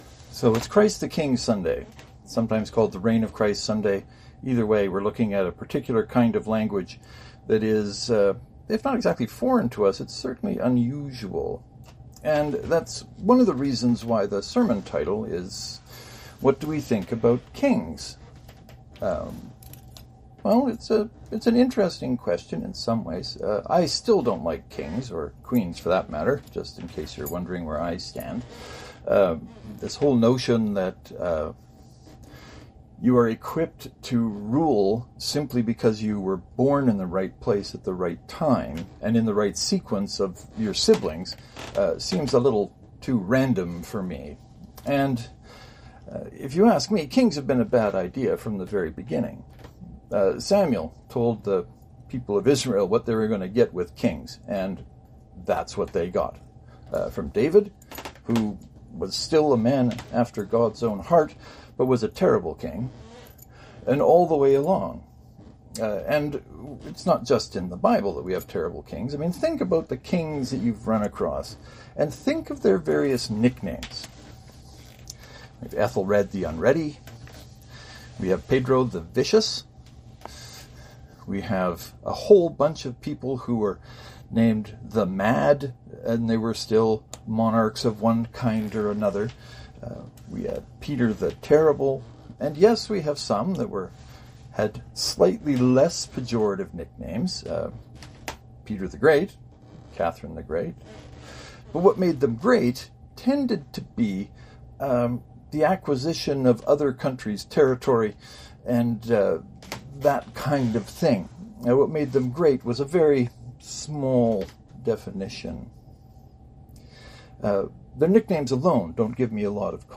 This Sunday’s sermon is a collection of thoughts about what it means to have Christ as King.